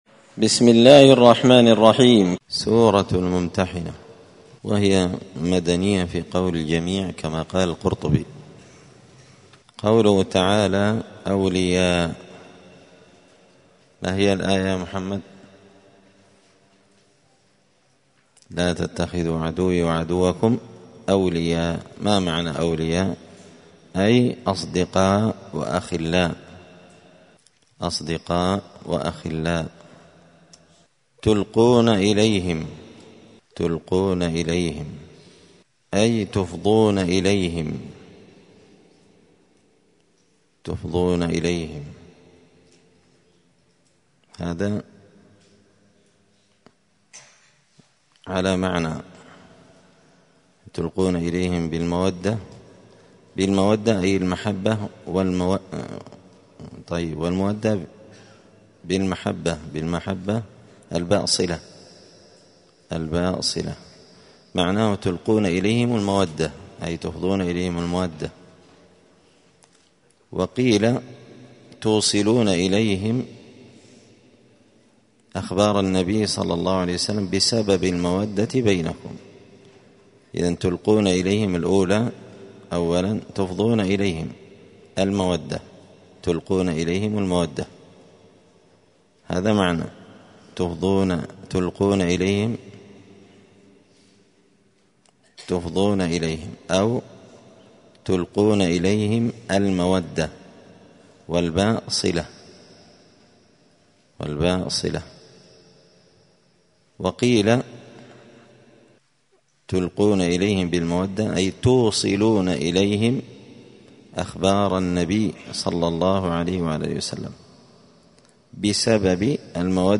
*(جزء المجادلة سورة الممتحنة الدرس 133)*